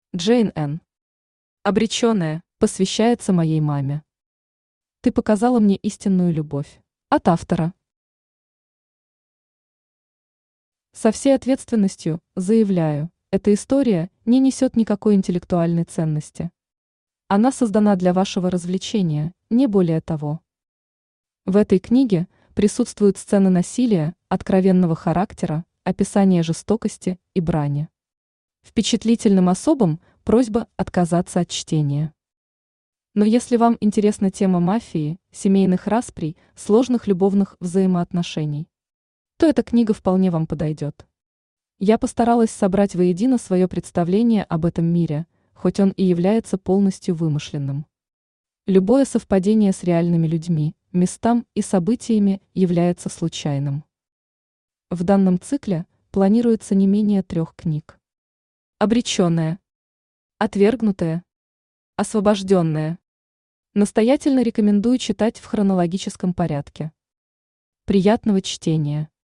Аудиокнига Обреченные | Библиотека аудиокниг
Aудиокнига Обреченные Автор Jane Ann Читает аудиокнигу Авточтец ЛитРес.